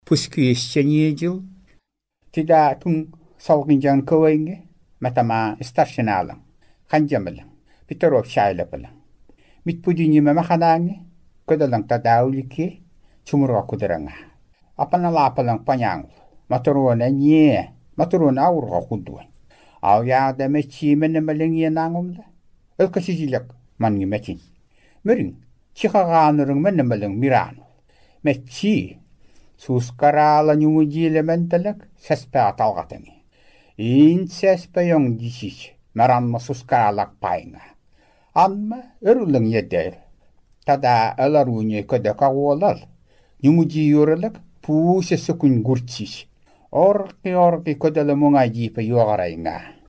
Тексты представляют собой естественные нарративы, записанные в текстовом виде и позже озвученные другим человеком (аудио к некоторым отрывкам прилагаются).
Озвучка текстов 2 (3[2])